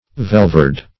velverd - definition of velverd - synonyms, pronunciation, spelling from Free Dictionary Search Result for " velverd" : The Collaborative International Dictionary of English v.0.48: Velverd \Vel"verd\, n. The veltfare.